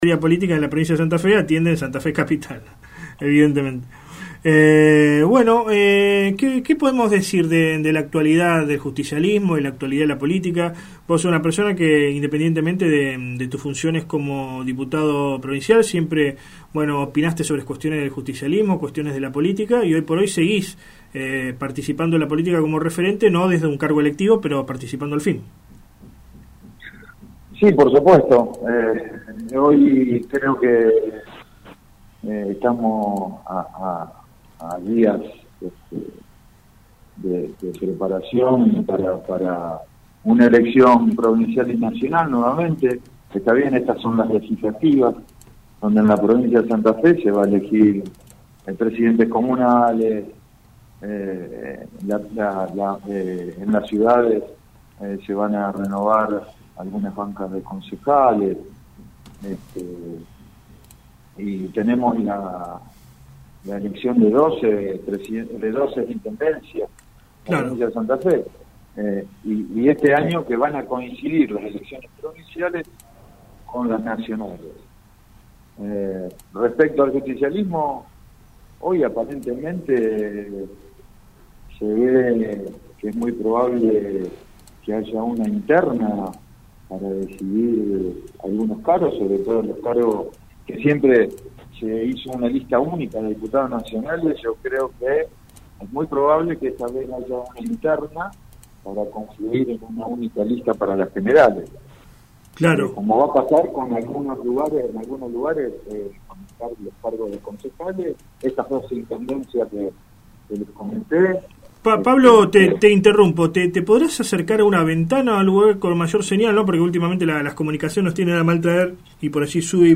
PABLO DI BERT AUDIO ENTREVISTA Se presenta en sociedad una nueva corriente política identificada con el justicialismo opositor al kirchenrista Agustín Rossi.